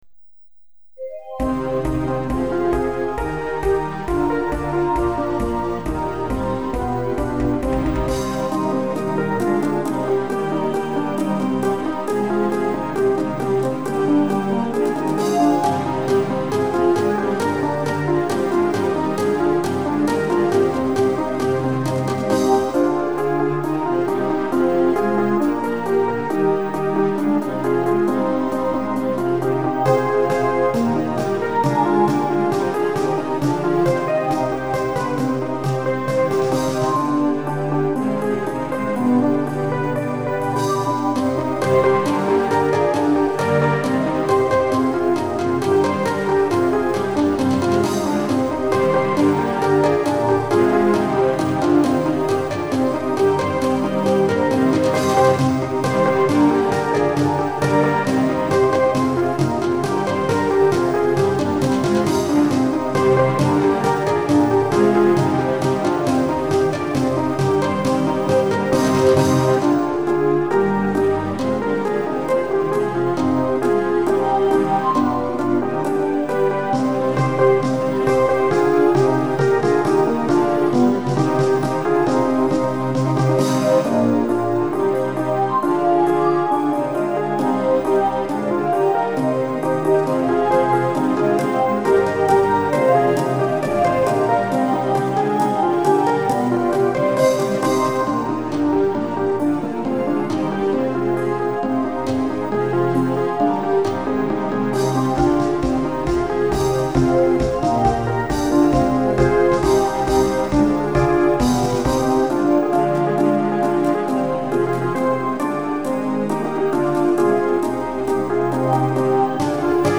〜カラオケ版〜